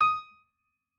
piano6_29.ogg